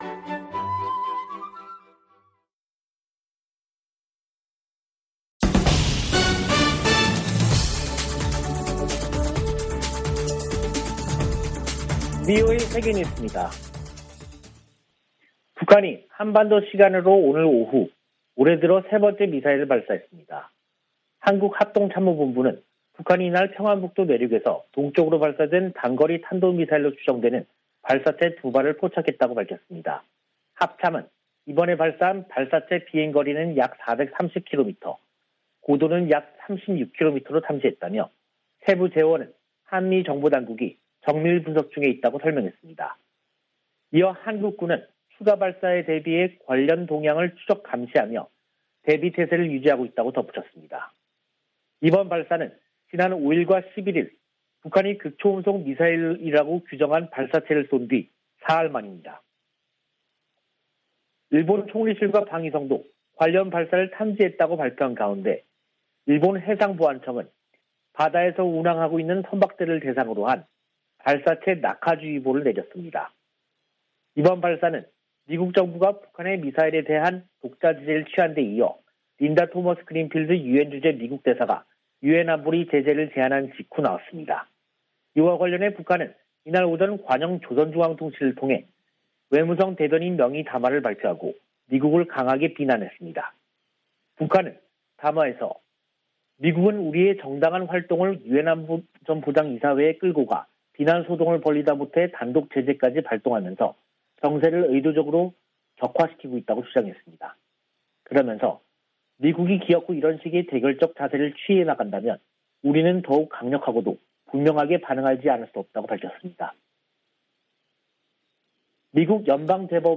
VOA 한국어 간판 뉴스 프로그램 '뉴스 투데이', 2022년 1월 14일 2부 방송입니다. 북한이 사흘 만에 또 단거리 탄도미사일로 추정되는 발사체 2발을 쐈습니다. 토니 블링컨 미 국무장관은 북한이 대화 제의에 미사일로 화답했다며, 책임을 물을 것이라고 강조했습니다. 북한의 미사일 부품 조달에 관여해 미국의 제재 명단에 오른 북한 국적자들이 유엔 안보리 제재 대상 후보로 지명됐습니다.